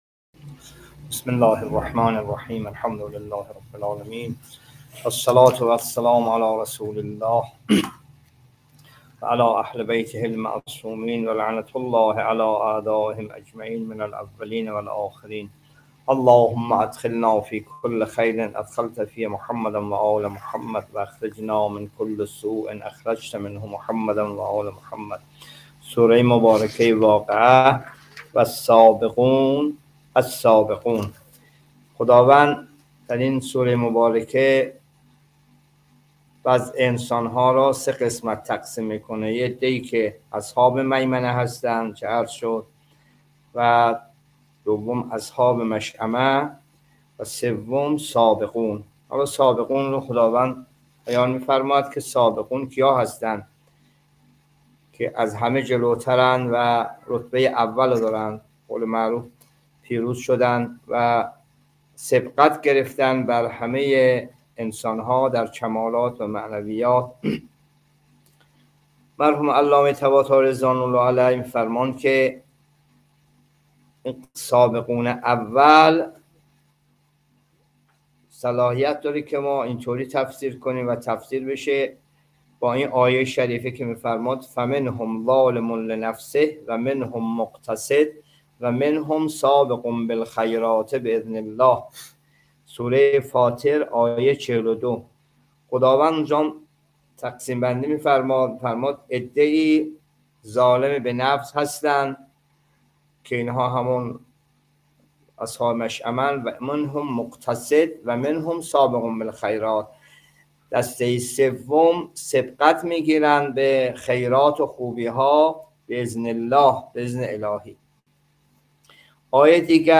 جلسه تفسیر قرآن (32) سوره واقعه